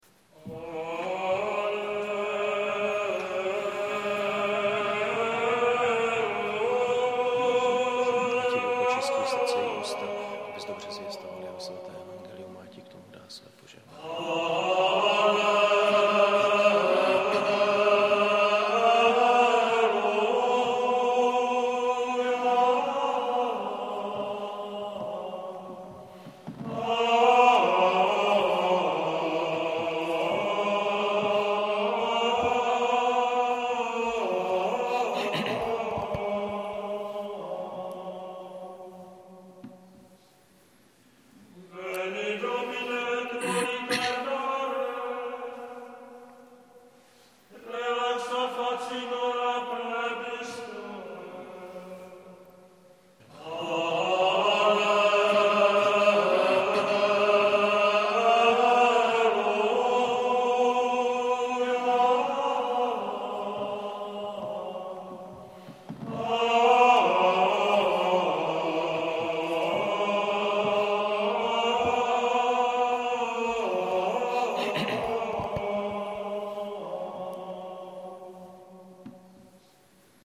Gregoriánský chorál
4. neděle adventní